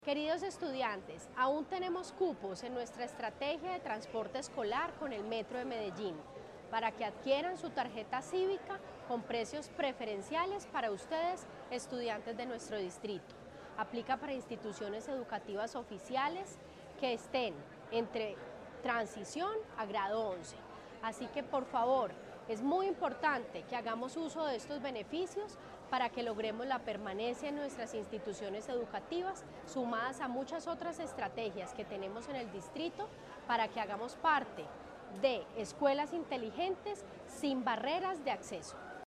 Declaraciones secretaria de Educación, Carolina Franco Giraldo.
Declaraciones-secretaria-de-Educacion-Carolina-Franco-Giraldo.-Ahorro-estudiantes.mp3